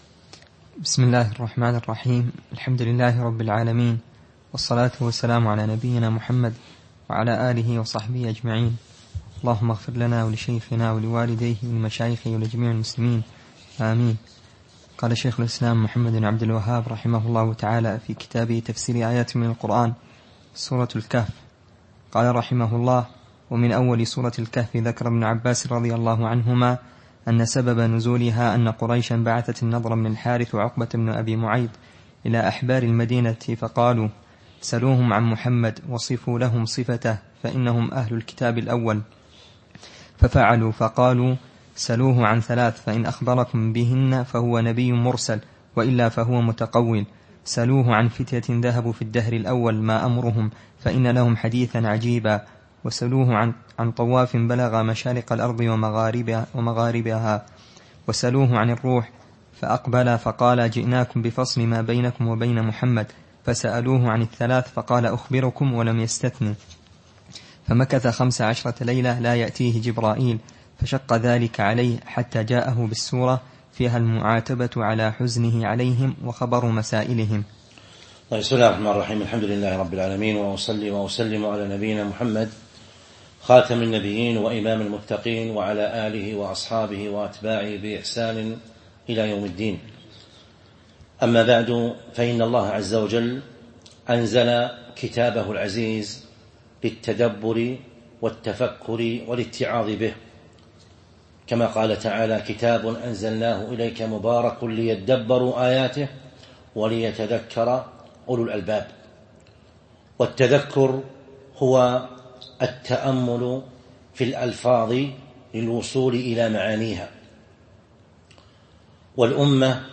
تاريخ النشر ١٥ صفر ١٤٤٢ هـ المكان: المسجد النبوي الشيخ